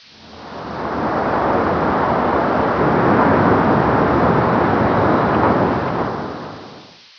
ambient2.wav